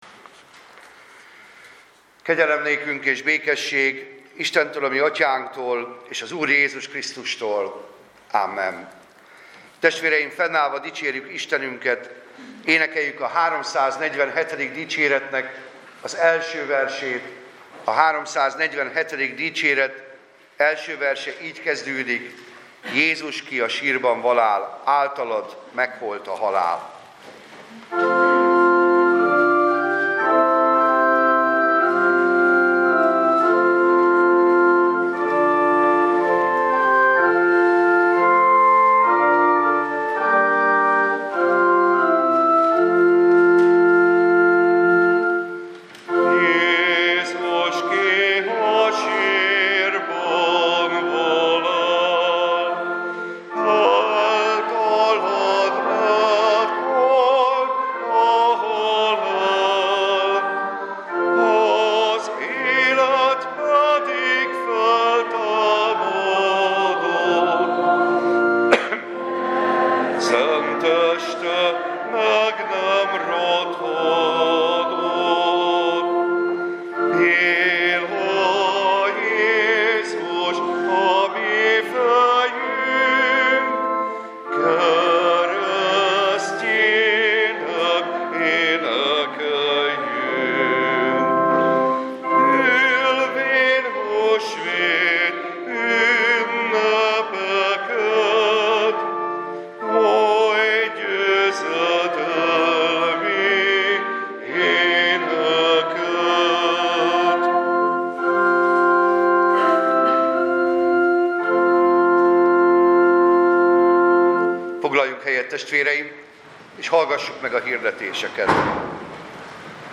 Passage: Lukács evangéliuma 24, 1-13 Service Type: Igehirdetés